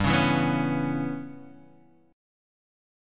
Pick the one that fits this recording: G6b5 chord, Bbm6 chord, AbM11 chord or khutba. G6b5 chord